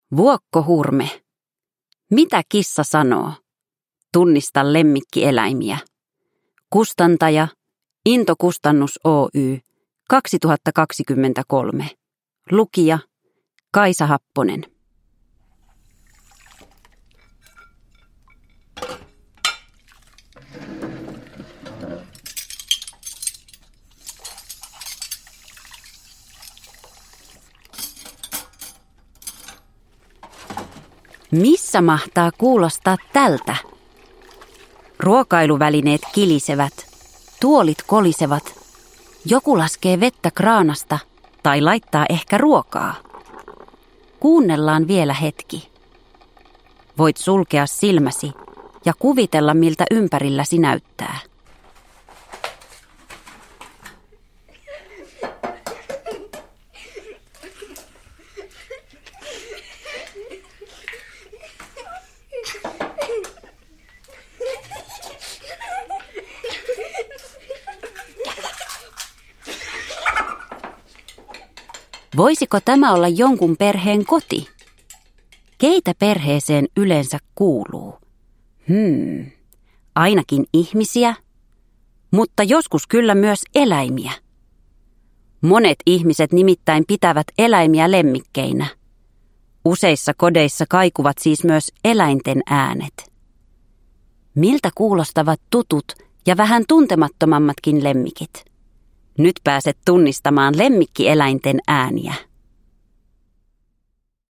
Ljudbok
Mitä kissa sanoo -äänikirja kuljettaa kuulijan kotiympäristöön keskelle tuttujen lemmikkieläinten ääniä.
Villit ja vinkeät eläinäänet, metsän tunnelmalliset suhinat ja monenmoiset rapinat vievät lapsen suoraan tapahtumapaikoille.